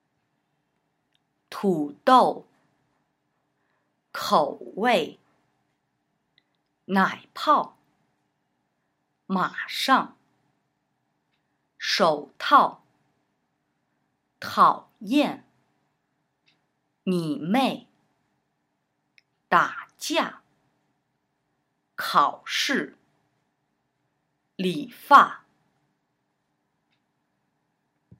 Übung 14: Töne 3+4